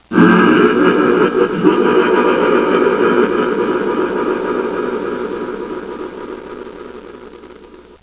pain75_2.wav